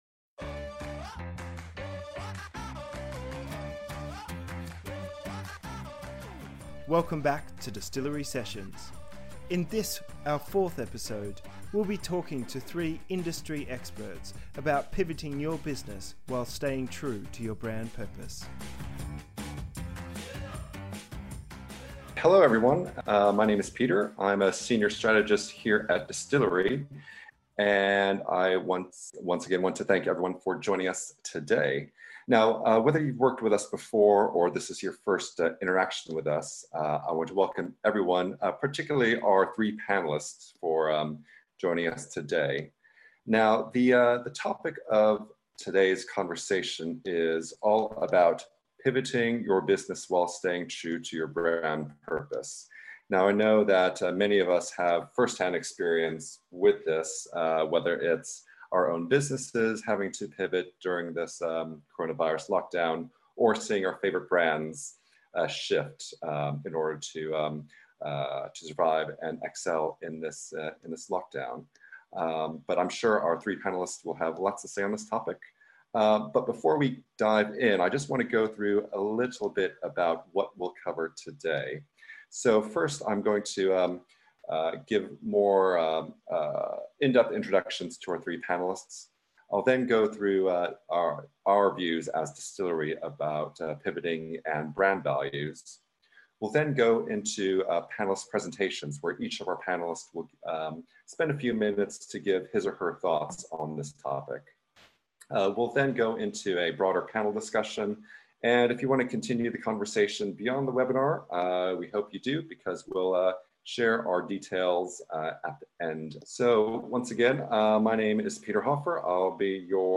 In this episode, we speak with a panel of experts to discuss the ways brands are innovating through tougher constraints and highlight practical tips to keep these innovations true to your draft values.